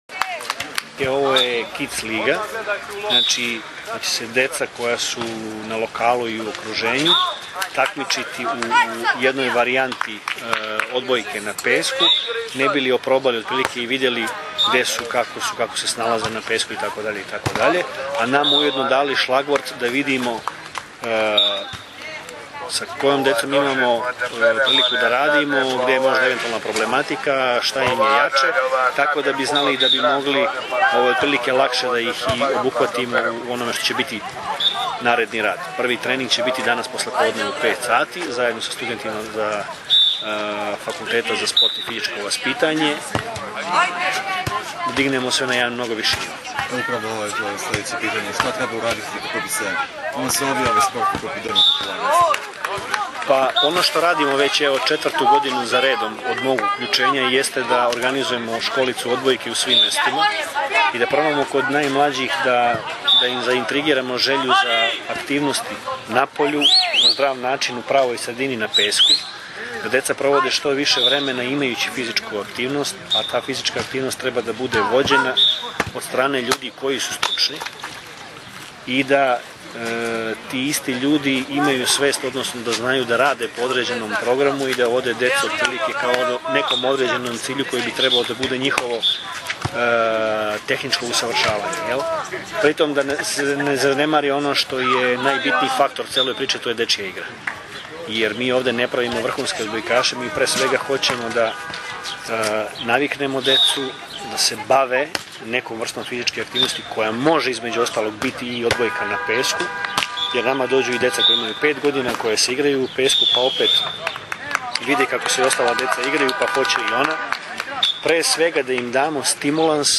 IZJAVA VLADIMIRA GRBIĆA